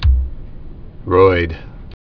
(roid)